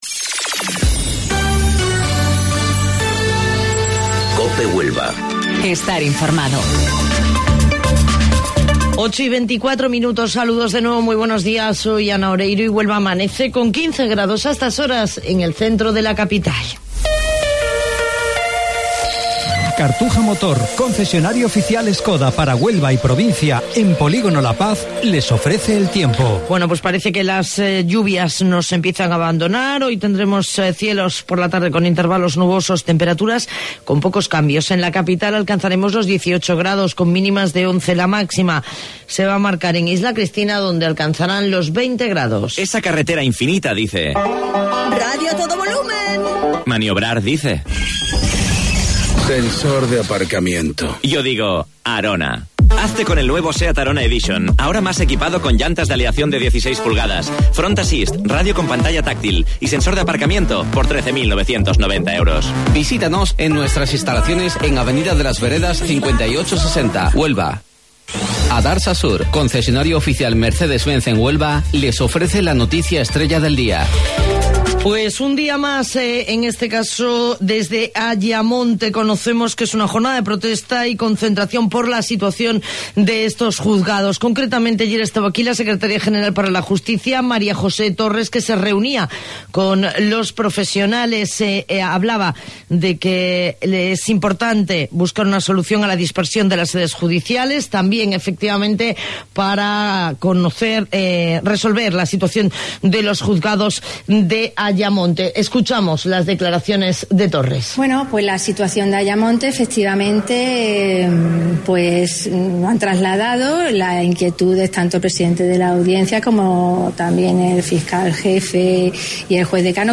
AUDIO: Informativo Local 08:25 del 26 de Abril